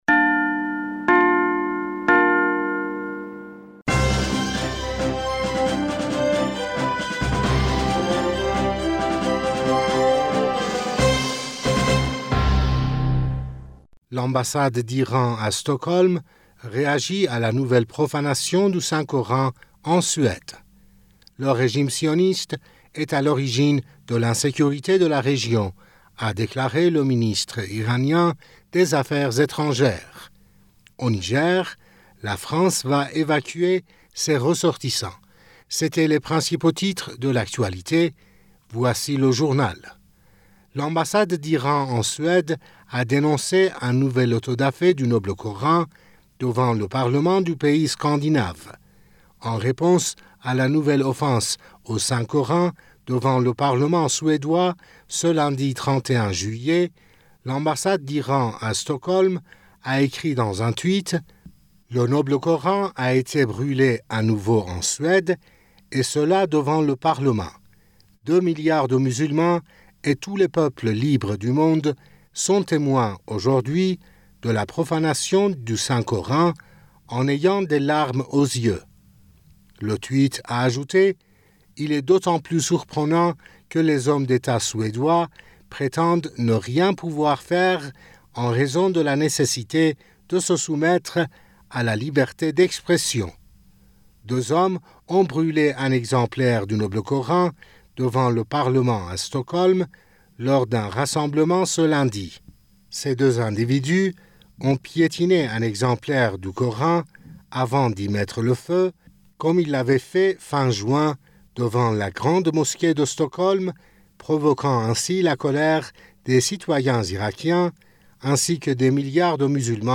Bulletin d'information du 01 Aout 2023